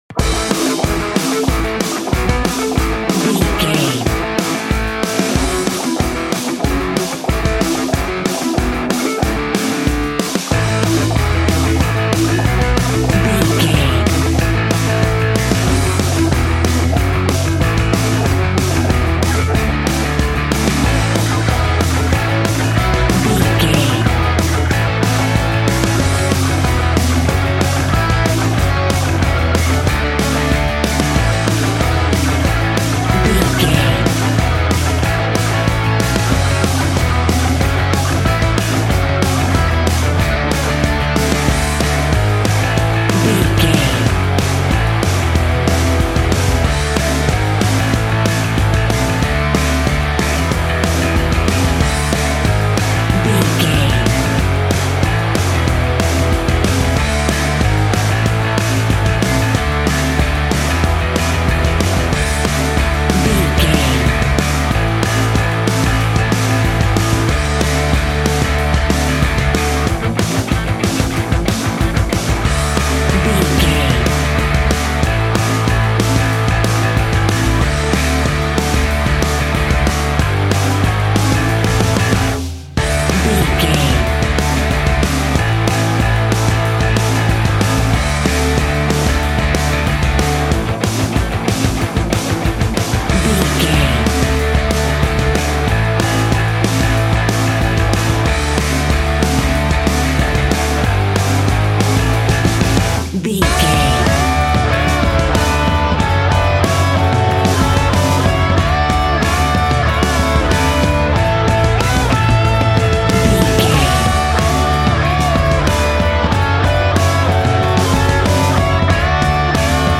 Ionian/Major
electric organ
drums
electric guitar
bass guitar